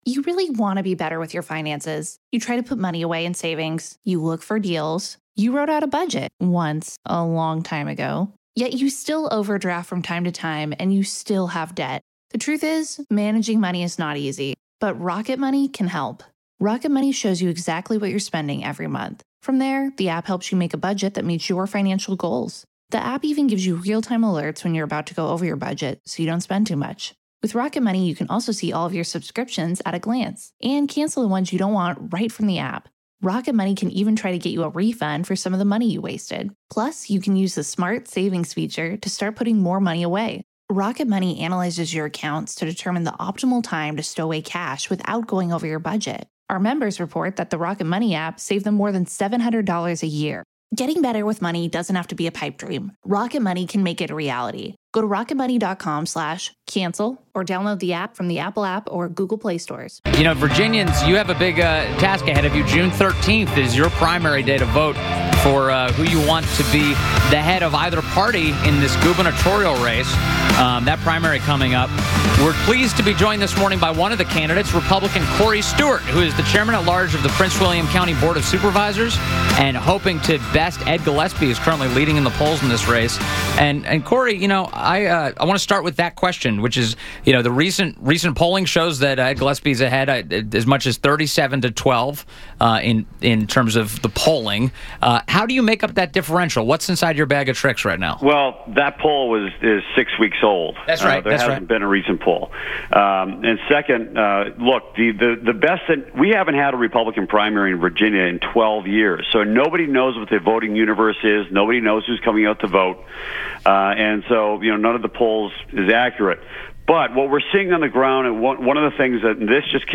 WMAL Interview - Corey Stewart 06.07.17